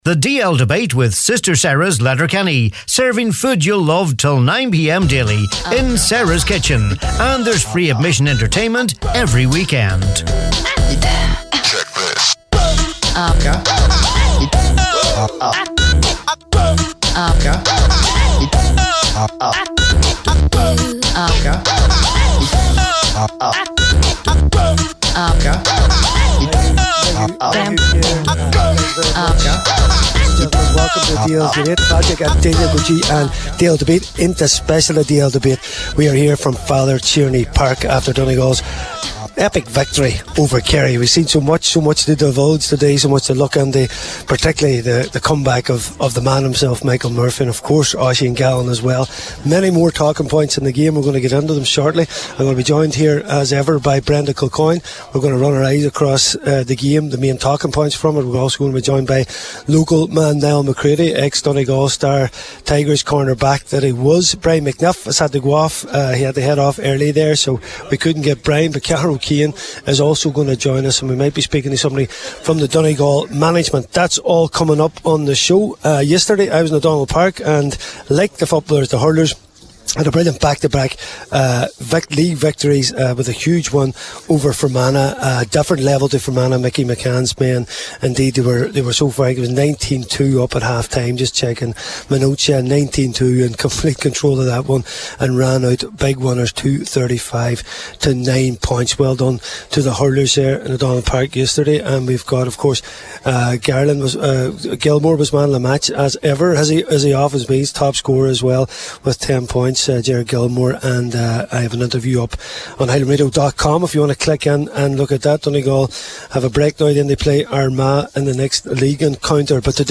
This week’s DL Debate was a live broadcast on Bank Holiday Sunday following the Donegal Kerry game in Ballyshannon.